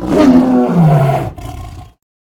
combat / creatures / tiger / he / die1.ogg
die1.ogg